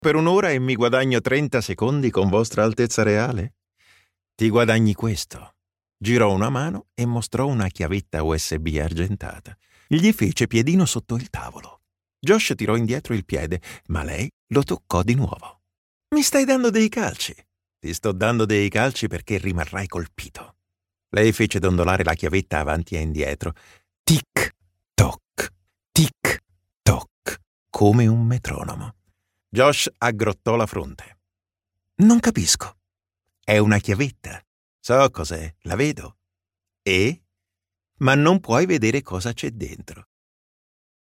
Male
Versatile, Corporate, Deep, Warm
Audio equipment: The recordings are made in my home studio equipped with soundproof booth, Neumann tlm 103 microphone, Apollo MKII SOUND CARD which guarantees white quality